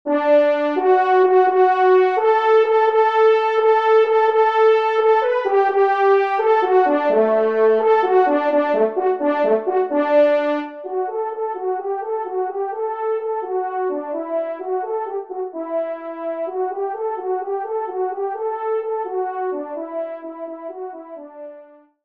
TROMPE 1 en Exergue